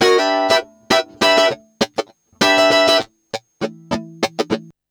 100FUNKY08-R.wav